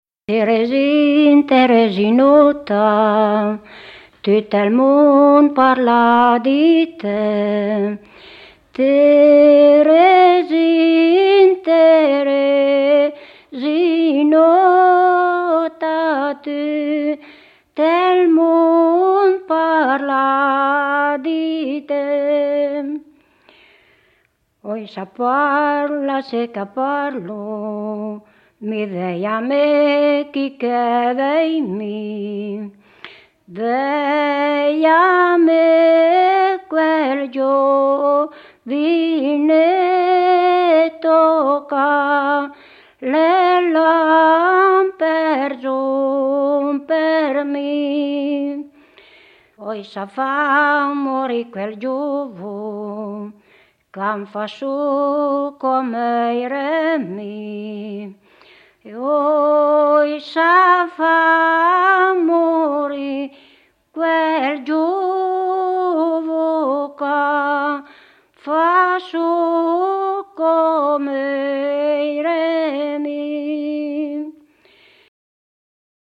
Fior di tomba / [registrata a San Damiano d'Asti (AT), nel 1981